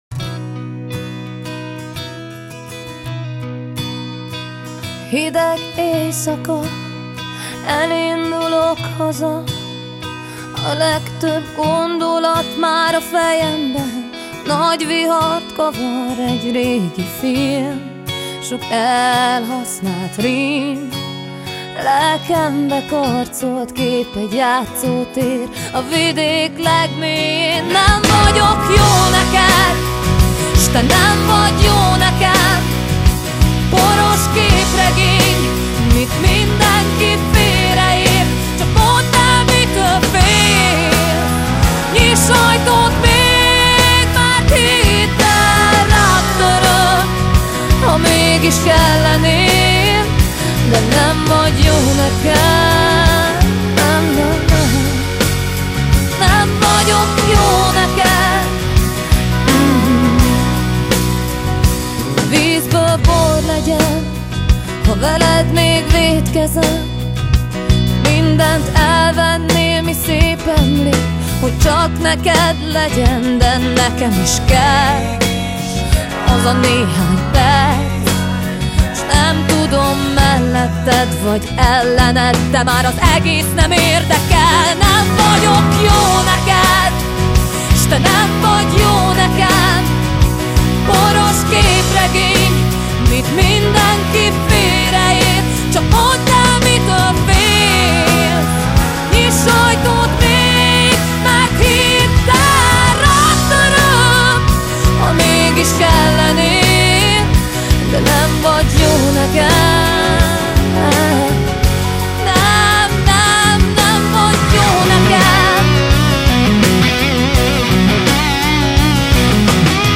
Ruig en toch erg mooi!